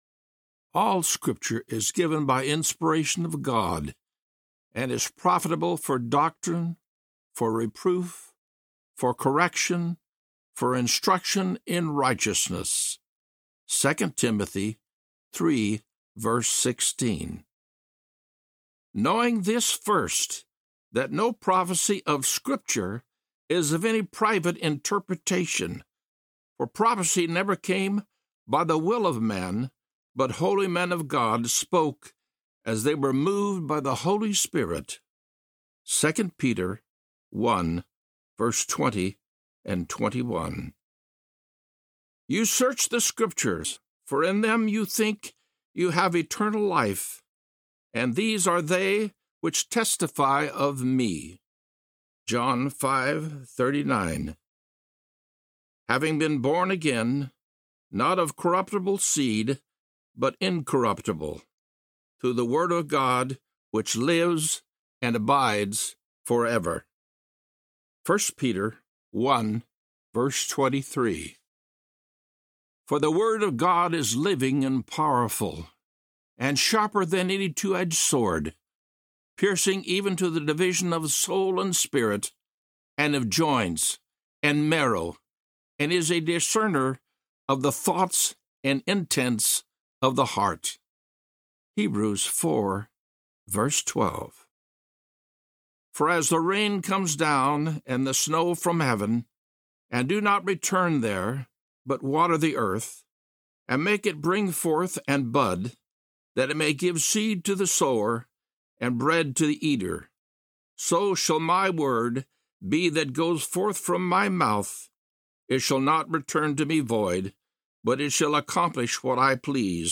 God’s Promises for Your Every Need (God’s Promises) Audiobook